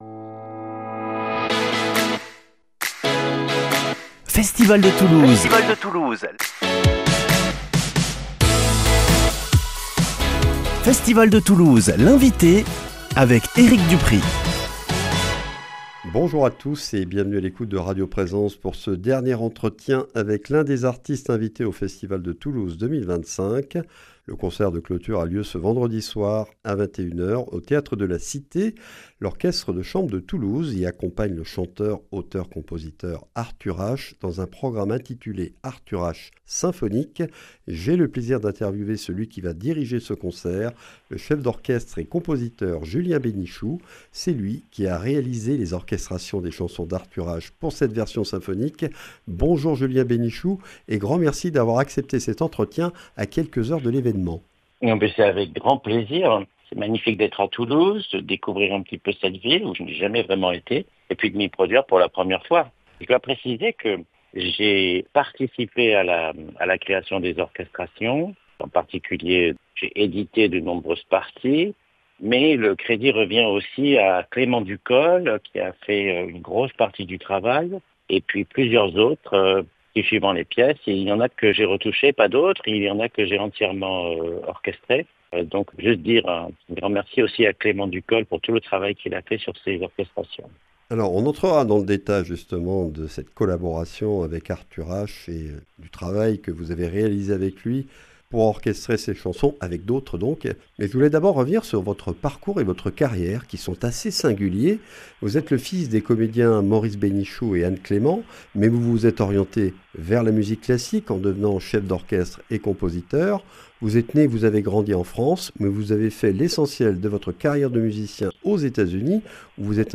Festival de Toulouse 2025 - Interview